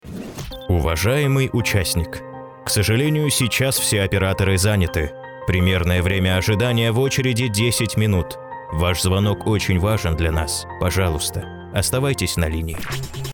Микрофон: SE ELECTRONICS sE2200a II C; Аудиоинтерфейс: Steinberg UR 12; Подготовленное помещение.